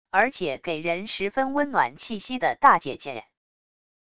而且给人十分温暖气息的大姐姐wav音频